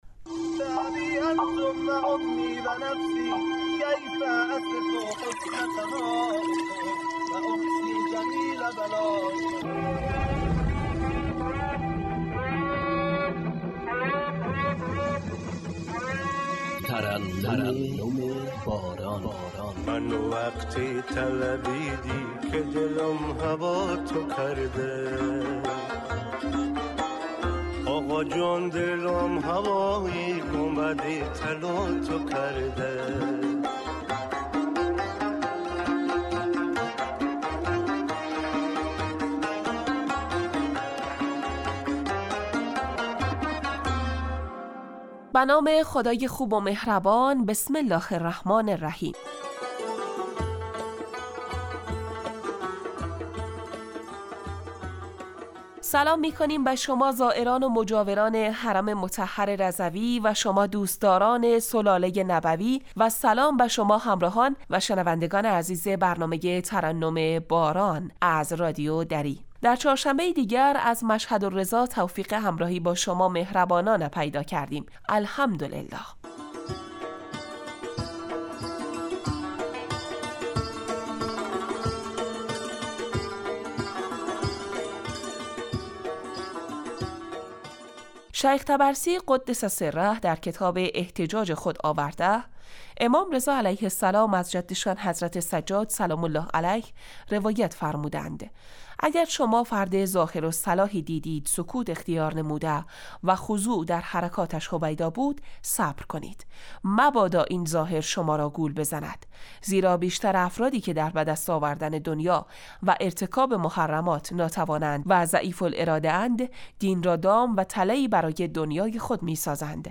این برنامه در روزهای چهارشنبه به مدت 15 دقیقه از رادیو دری پخش میشود و در آن به موضوع زیارت امام رضا«ع» می پردازیم.